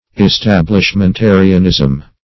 establishmentarianism \es*tab`lish*men*ta"ri*an*ism\, n.